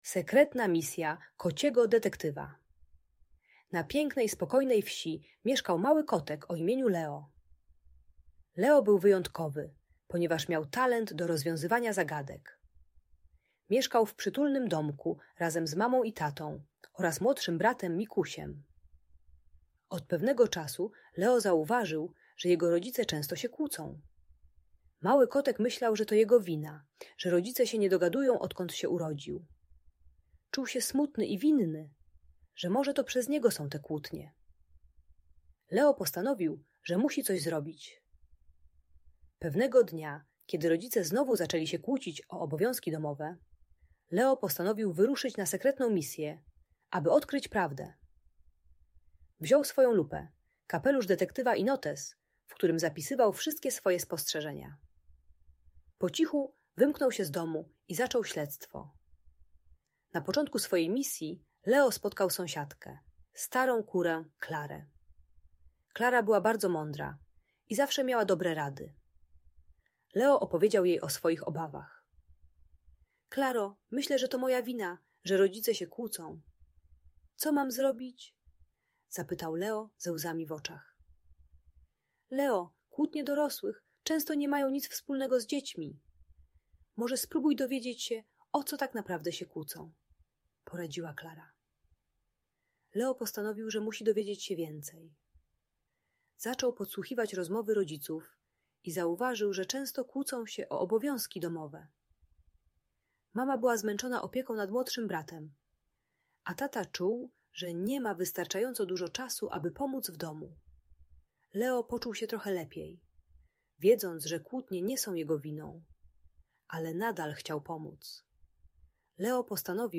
Sekretna Misja Kociego Detektywa - Emocje rodzica | Audiobajka